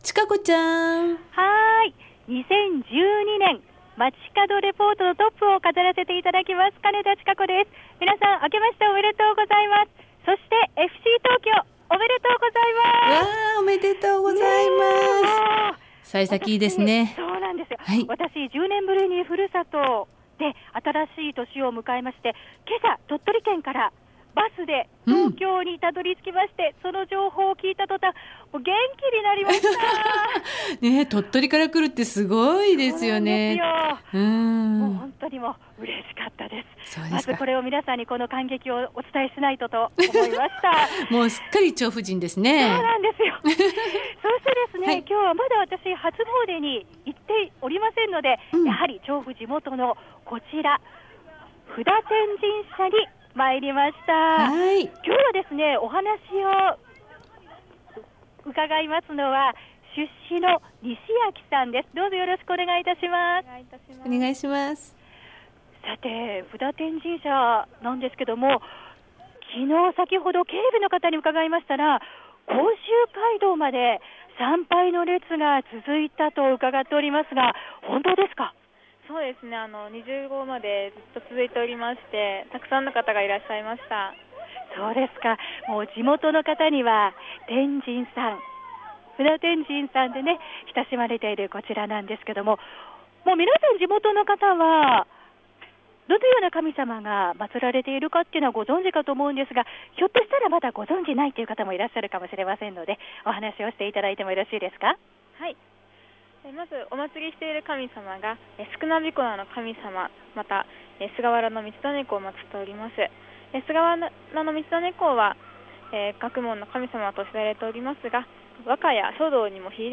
街角レポート
調布駅北口から歩いて６分の 布多天神社に初詣！